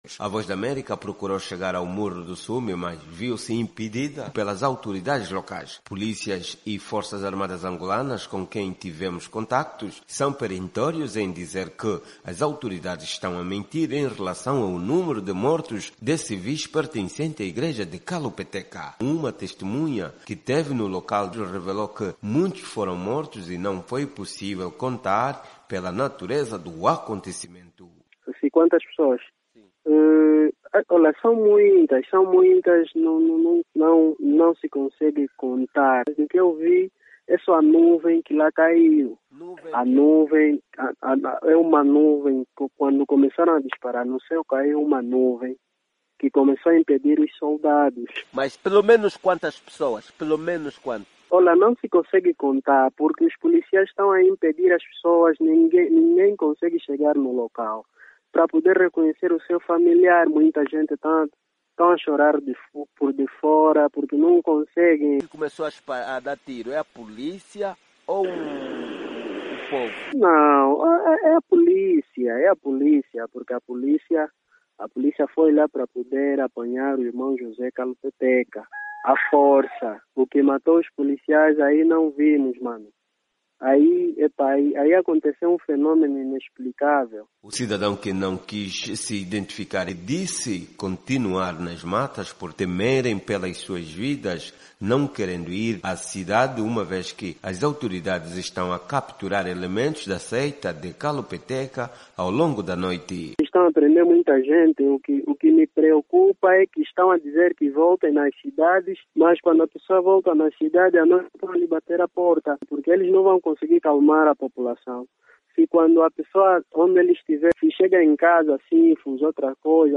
Uma testemunha dos confrontos entre a policia e fiéis da seita A Luz do Mundo disse à VOA que “muita gente” foi morta pela polícia.